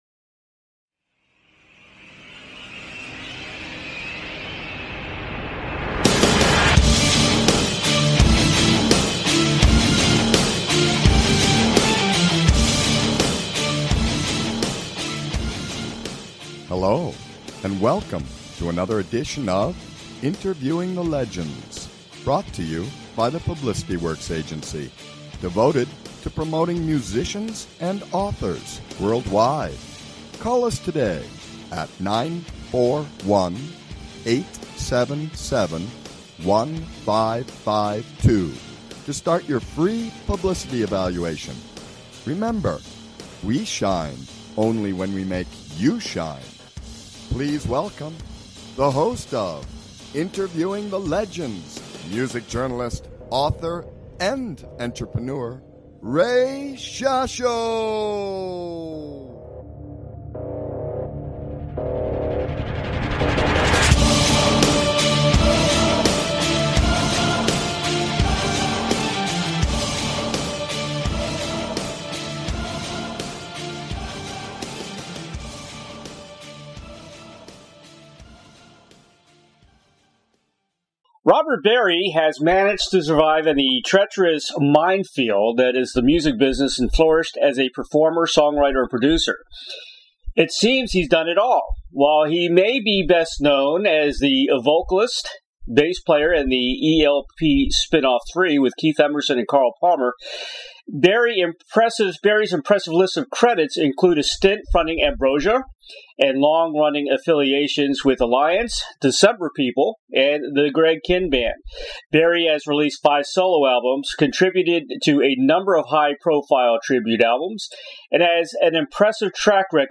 Talk Show Episode
Guest, Robert Berry